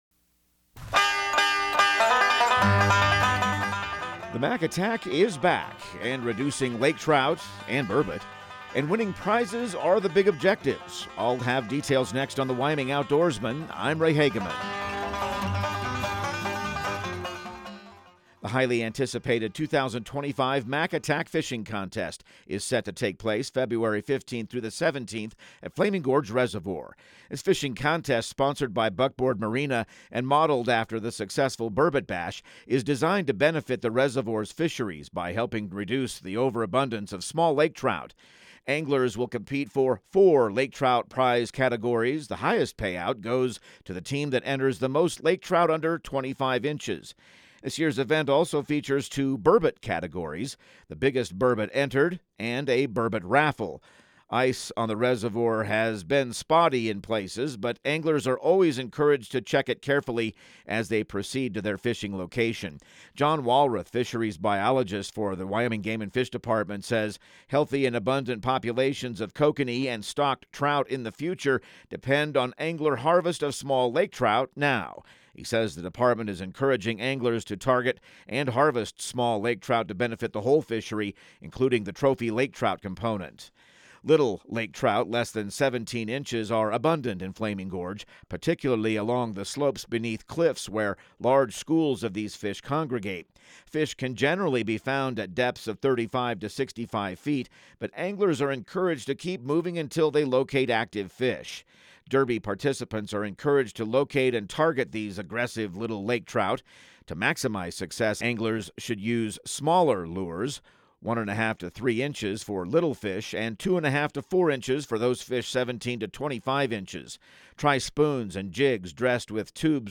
Radio news | Week of February 10